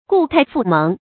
注音：ㄍㄨˋ ㄊㄞˋ ㄈㄨˋ ㄇㄥˊ
故態復萌的讀法